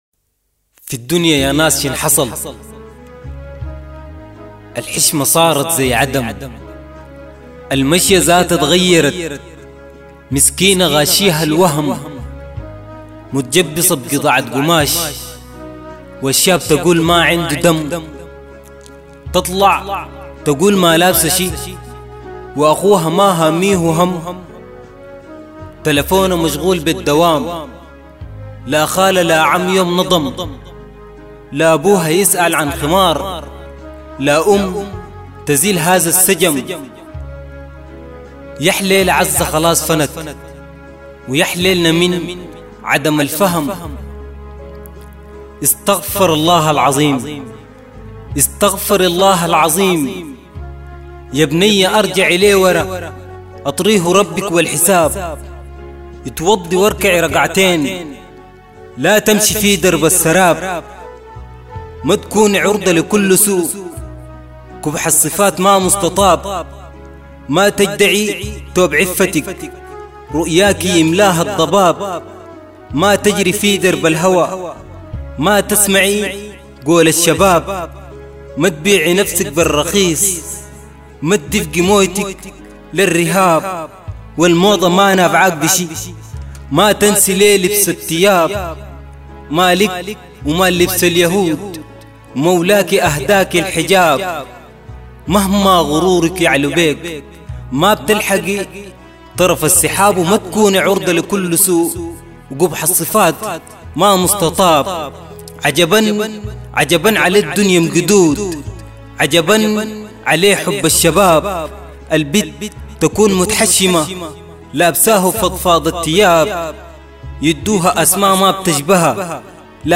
البوم صور   قصائد صوتية